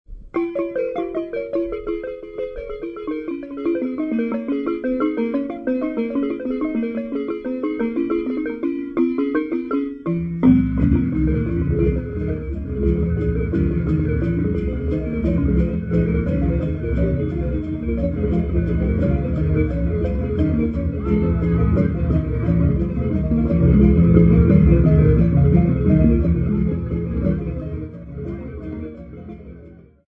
Ngodo of Chief Mikumbi
Folk music--Africa
Field recordings
sound recording-musical
First orchestMovement of the Ngodo of chief Mikumbi 1955, played on xylophones and rattles.